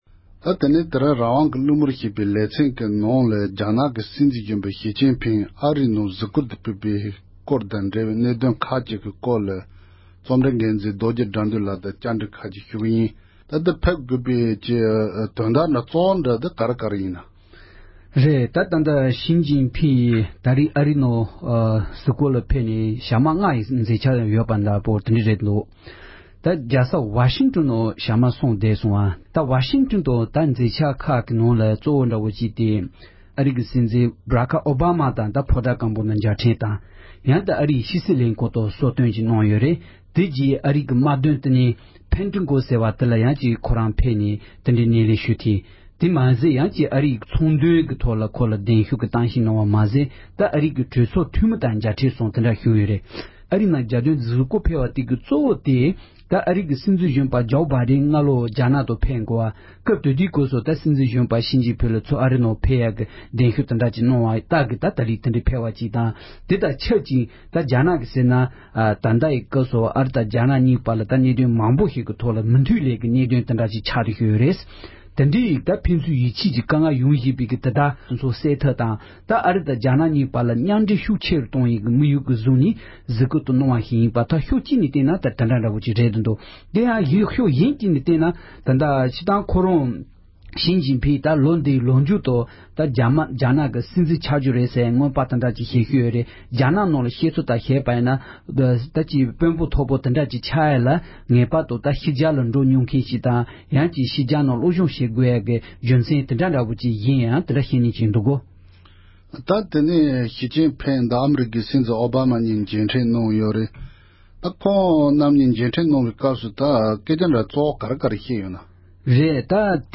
རྒྱ་ནག་གི་སྲིད་འཛིན་གཞོན་པ་ཞི་ཅིང་ཕིང་ཨ་རིའི་ནང་གཟིགས་བསྐོར་དང་འབྲེལ་བའི་གནད་དོན་སྐོར་གླེང་མོལ།